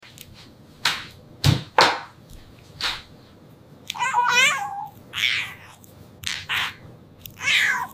Ai Kitten Toast Spread Asmr Sound Effects Free Download